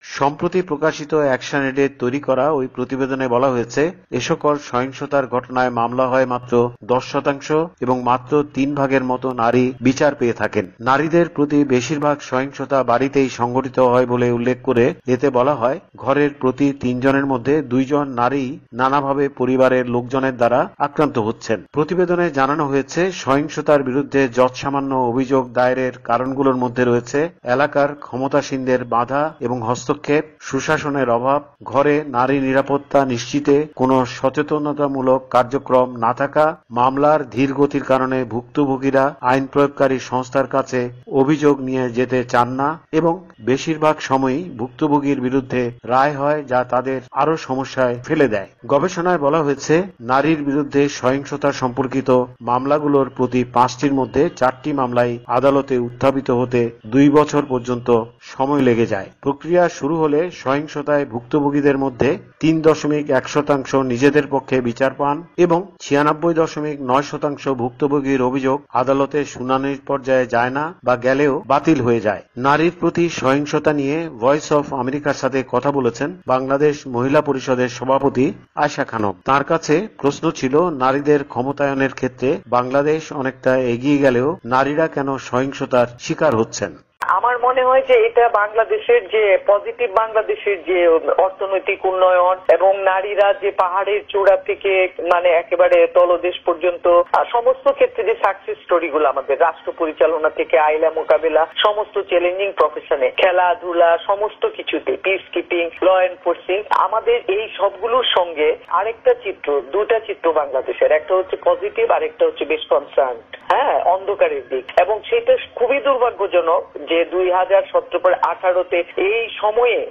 রিপোর্ট।